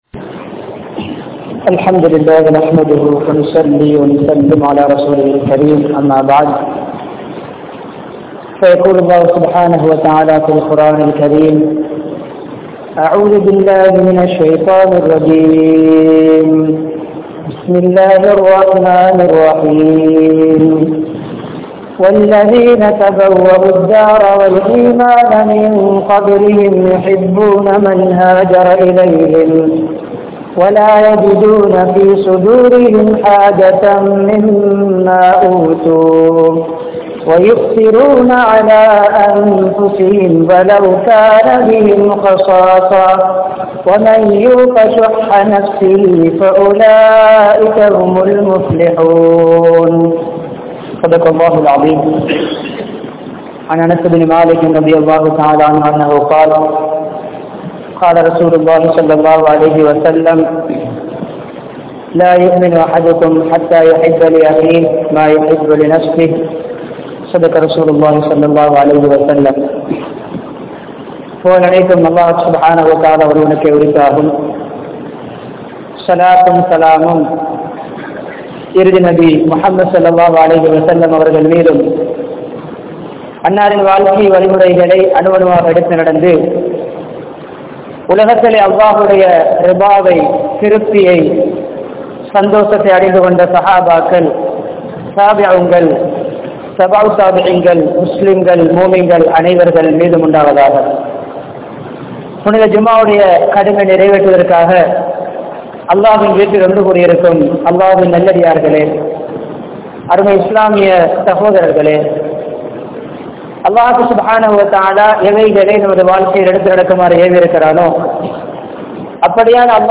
Suya Nalathin Vifareethangal (சுயநலத்தின் விபரீதங்கள்) | Audio Bayans | All Ceylon Muslim Youth Community | Addalaichenai
Panadura, Gorakana Jumuah Masjith